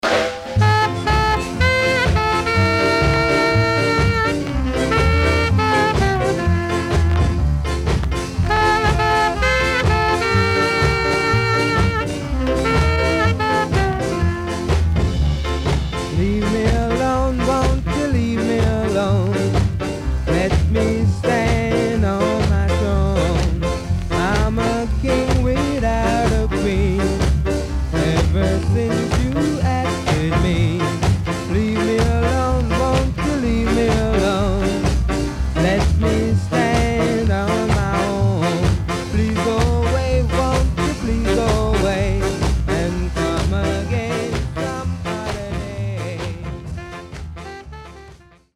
Authentic Ska Inst & Nice Ska Vocal.W-Side Good
SIDE A:軽いヒスノイズがあり、少しプチノイズ入ります。